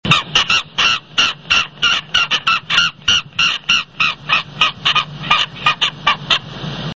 Iles Kerguelen - Skua subantarctique
Skua subantarctique. Anse de la Caverne.